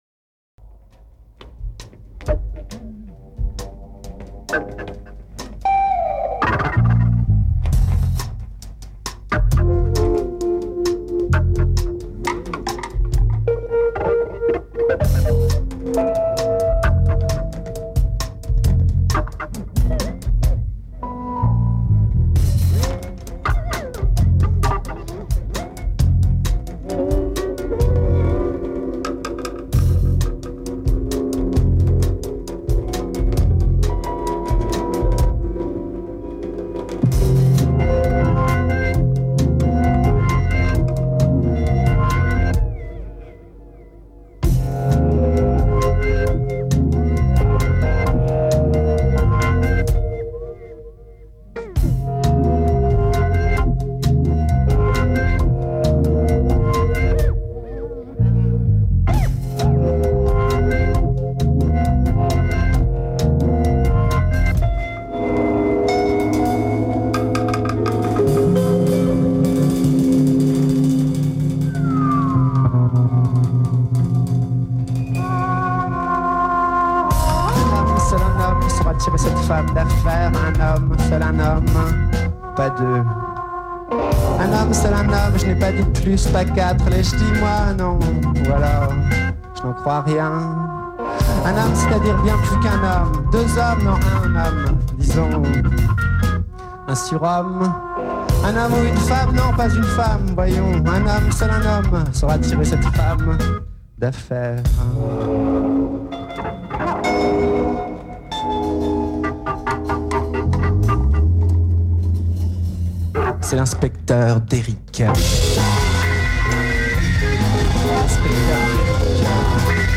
enregistrée le 12/03/2001  au Studio 105